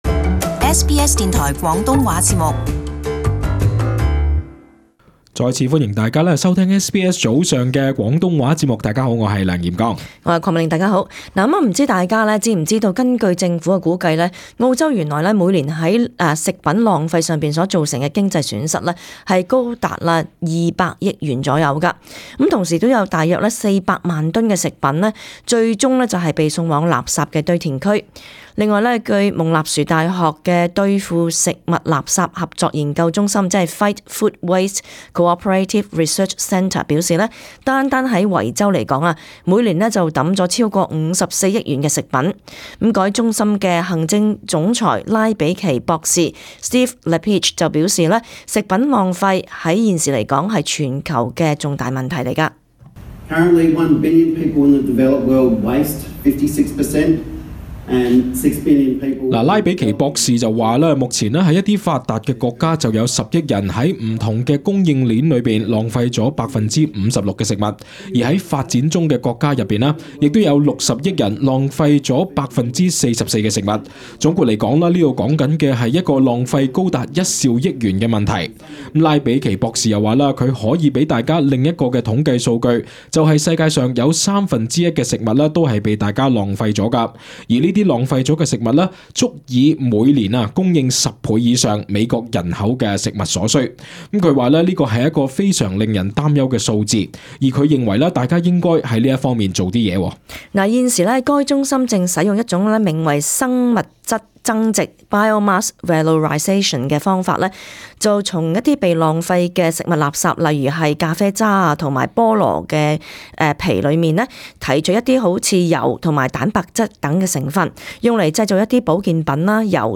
【時事報導】將剩餘食物轉化成保健品？